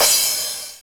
CYM CRA14.wav